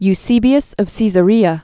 (y-sēbē-əs; sēzə-rēə, sĕsə-, sĕzə-) AD 260?-340?